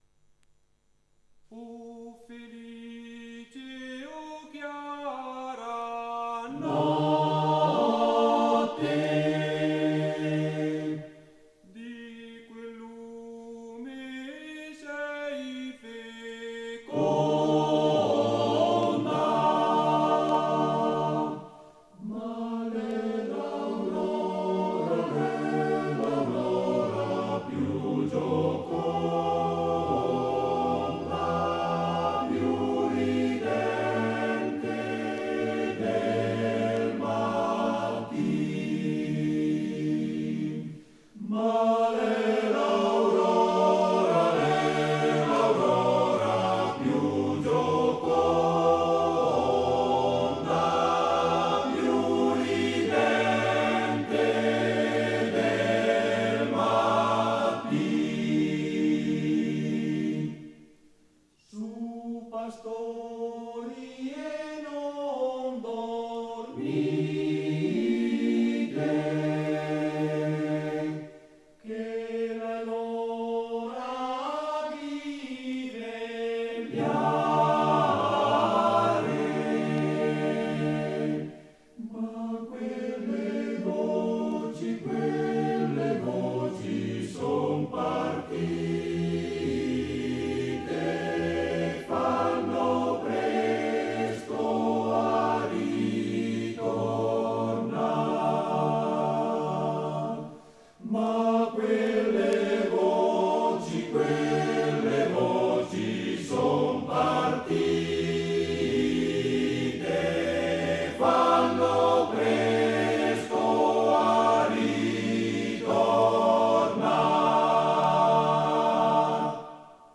Arrangiatore: Dionisi, Renato
Esecutore: Coro della SAT